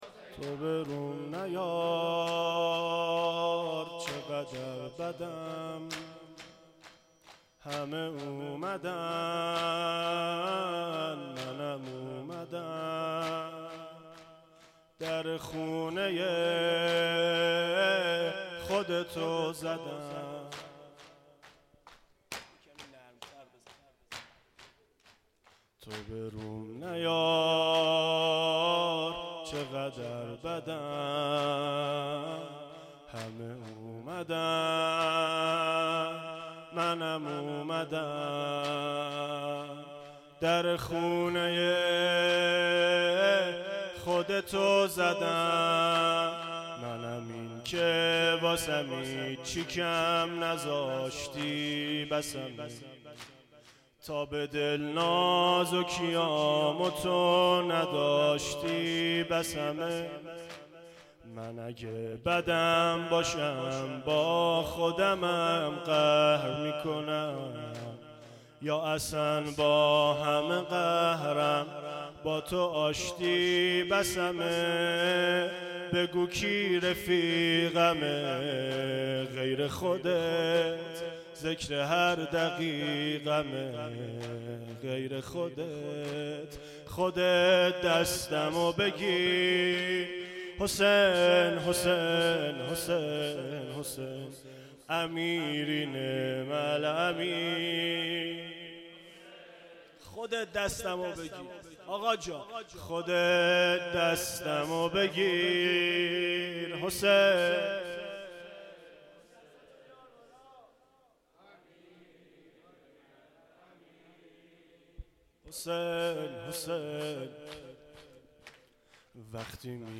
پایانی شب سوم فاطمیه
مداحی